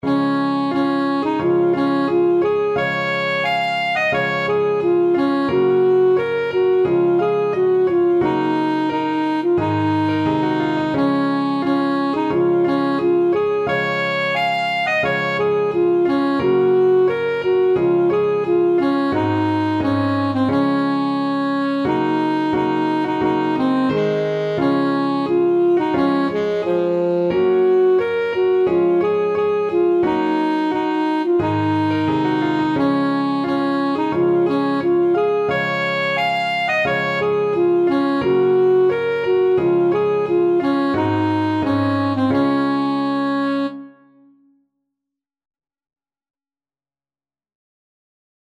Alto Saxophone
Db major (Sounding Pitch) Bb major (Alto Saxophone in Eb) (View more Db major Music for Saxophone )
4/4 (View more 4/4 Music)
Traditional (View more Traditional Saxophone Music)
scotland_the_brave_ASAX.mp3